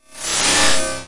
描述：这是我朋友公寓楼里非常老的电梯。
标签： 建筑噪声 声音效果 气氛
声道立体声